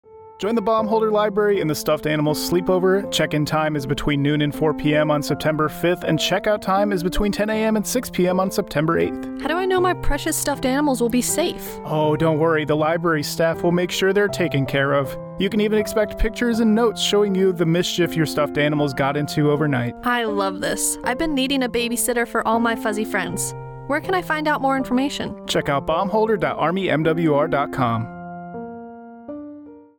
Radio Spot - Stuffed Animal Sleepover